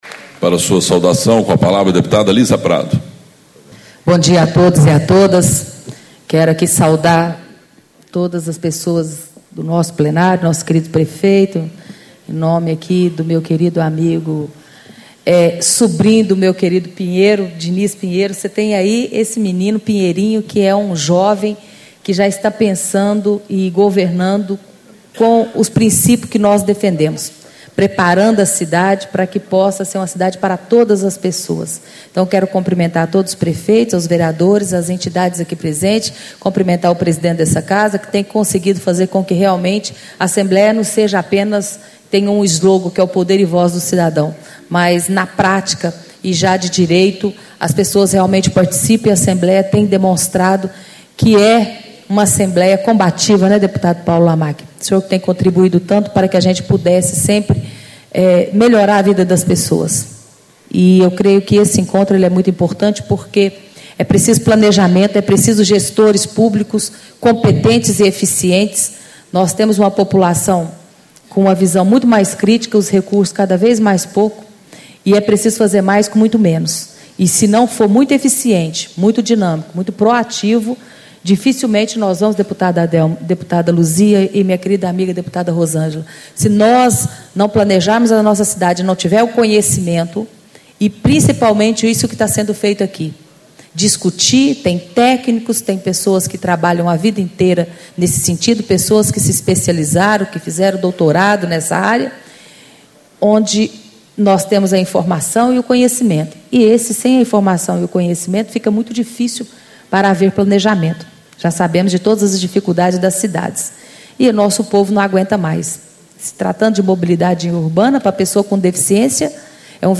Abertura - Deputada Liza Prado, PSB - Presidente da Comissão de Defesa da Pessoa com Deficiência
Encontro Estadual do Fórum Técnico Mobilidade Urbana - Construindo Cidades Inteligentes